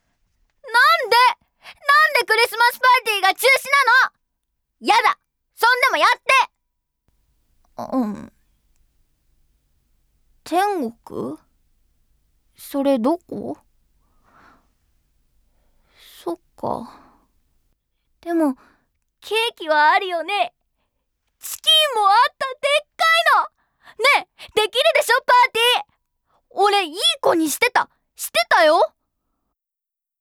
• セリフ02
④少年.wav